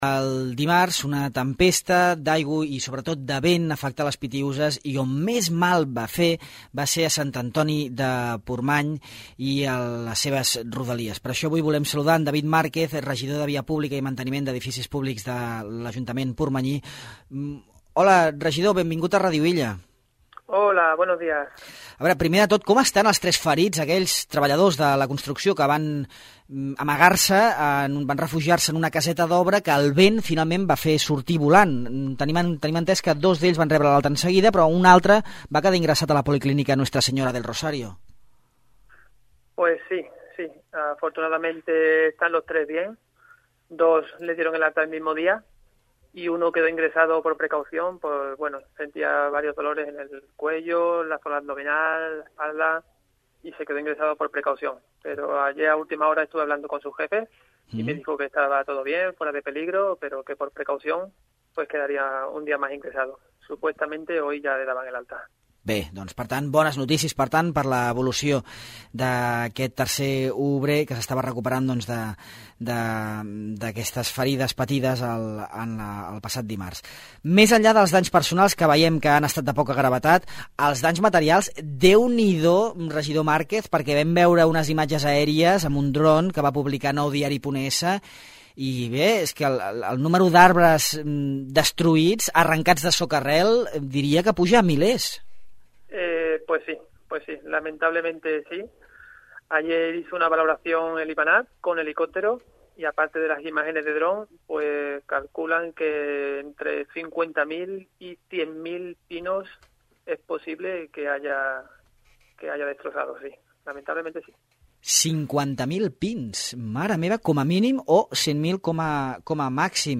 David Márquez, regidor de Via Pública i Manteniment d’Edificis Públics de l’Ajuntament de Sant Antoni de Portmany explica els estralls causats aquest dimarts pels cops de vent de 140 km/h, que han provocat nombrosos desperfectes i la caiguda de més de 50.000 arbres. A més, Márquez aborda les feines de reparació en què participen efectius de diversos cossos i que ja estan en marxa.